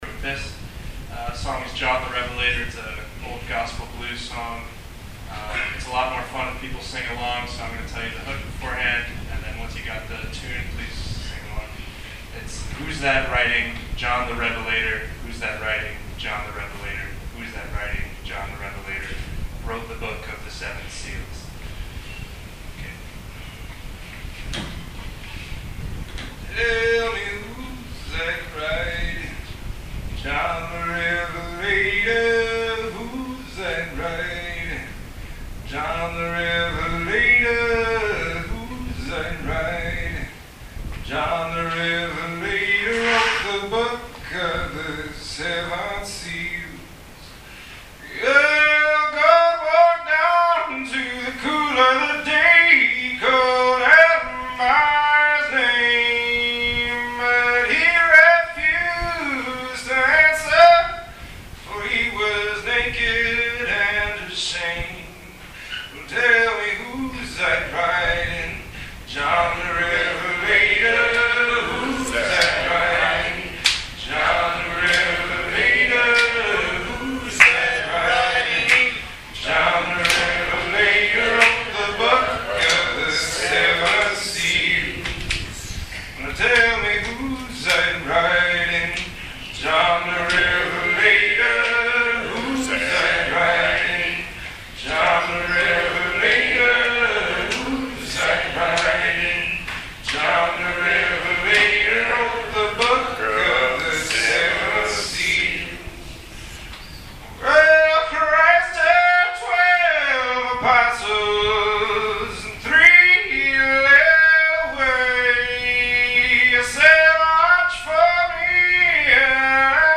At a talent show/concert during that weekend a college student working on the island for the summer (these students are called "pelicans) sang "John the Revelator" and I liked it so much I added it to this collection.